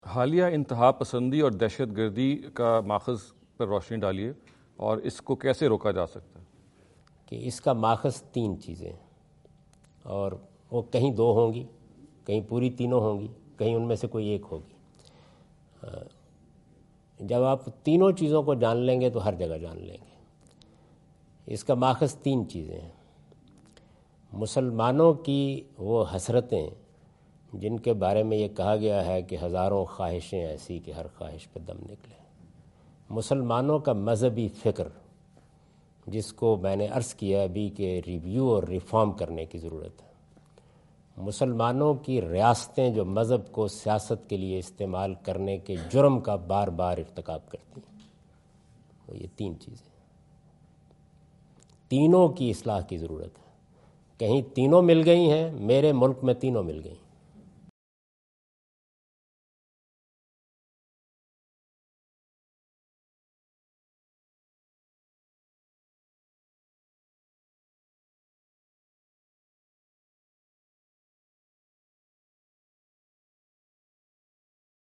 In this video Javed Ahmad Ghamidi answer the question about "root causes of extremism and terrorism" asked at East-West University Chicago on September 24,2017.